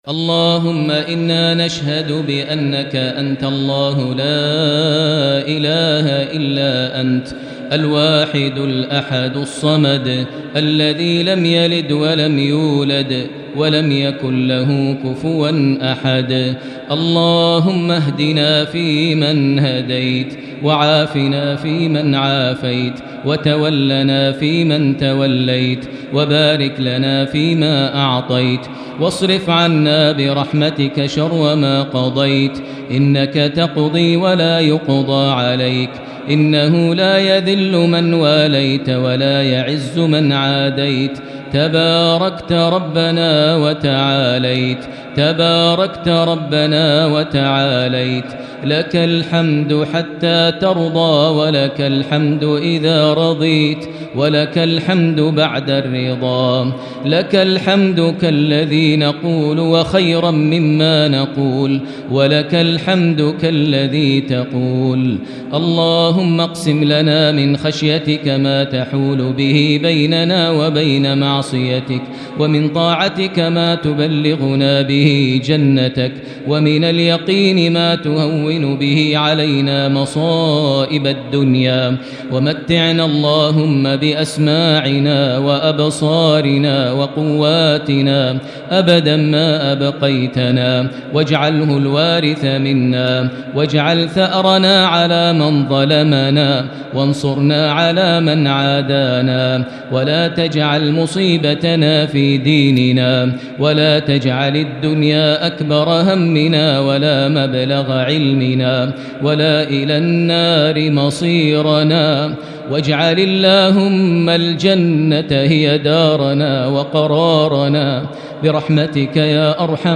دعاء القنوت ليلة 20 رمضان 1441هـ > تراويح الحرم المكي عام 1441 🕋 > التراويح - تلاوات الحرمين